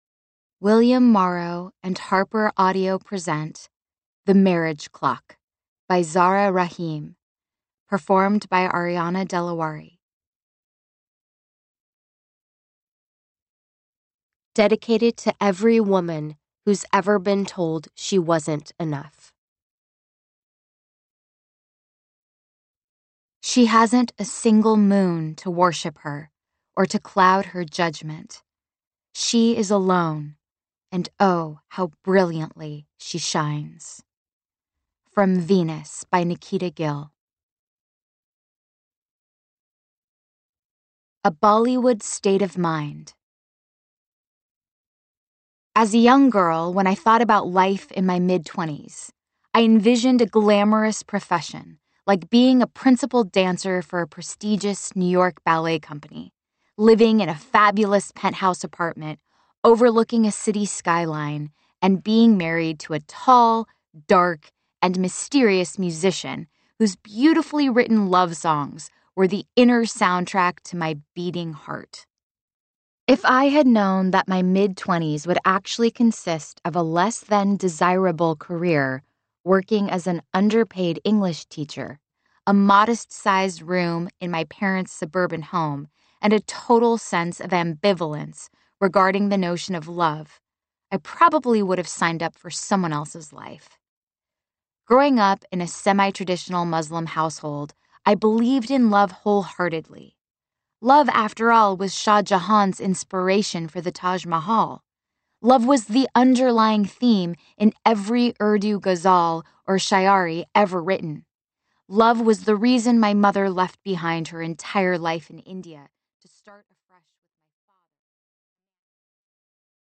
Unabridged
Audiobook